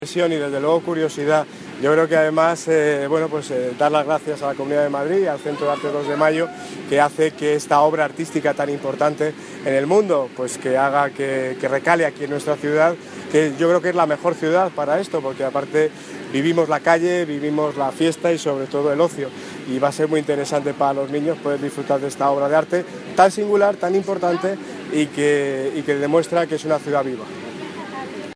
Audio - Daniel Ortiz (Alcalde de Móstoles) Sobre Hinchable en Móstoles